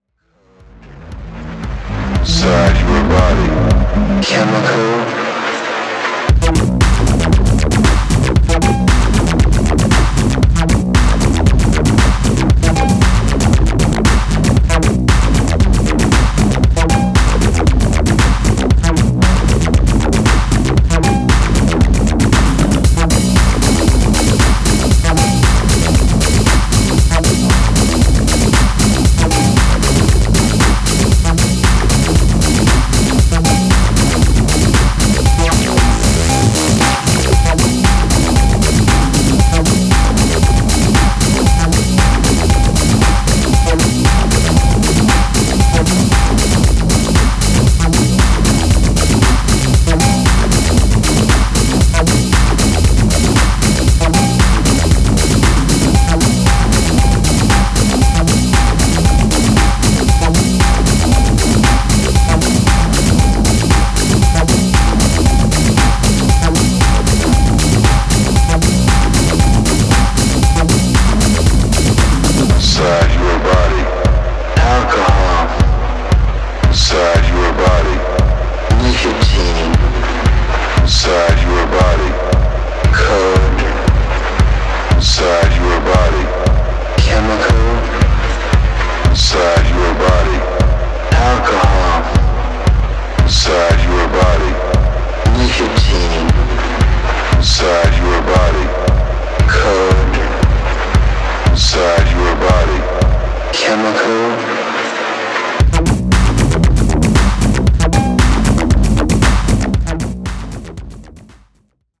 錆び付いたアシッドリフとフリーキーなハイトーンリフの奏でる残忍なハーモニー